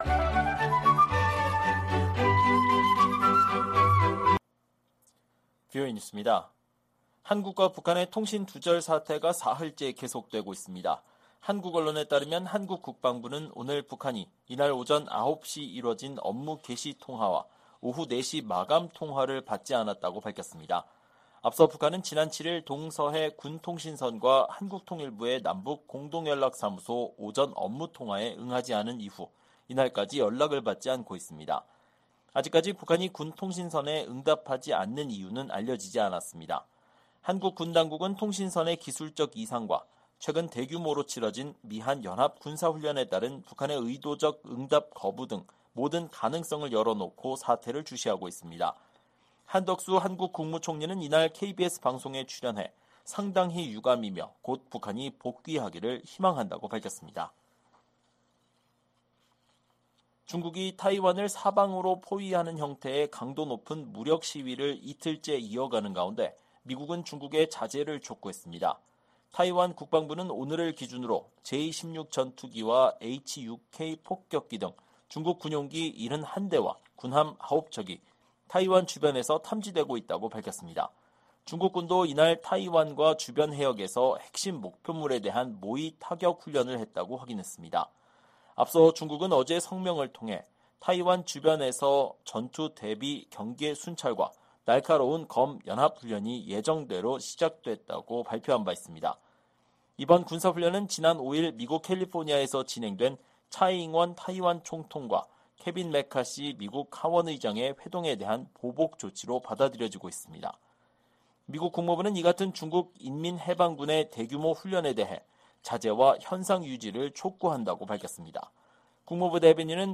VOA 한국어 방송의 일요일 오후 프로그램 3부입니다.